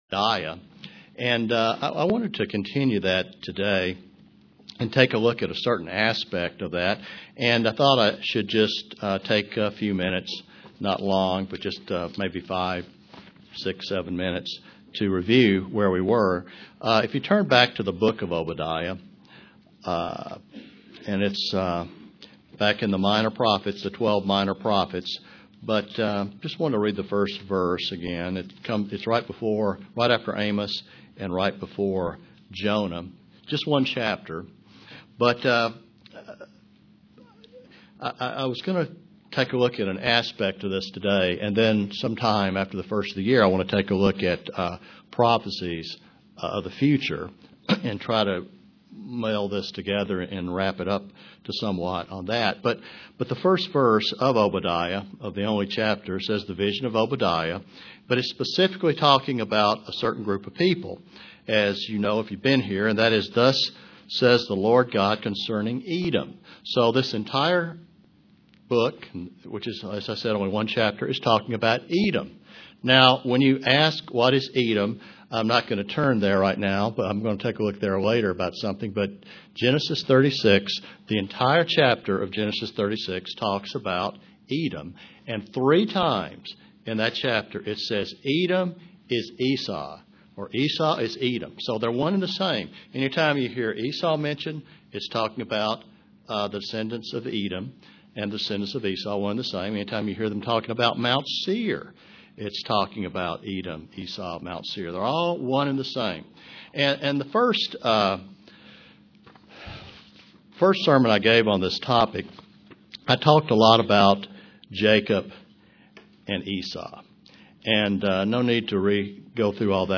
Print Tracing the history of the tribes of Esau--in particular the Amalekites UCG Sermon Studying the bible?